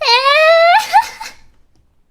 Worms speechbanks
Laugh.wav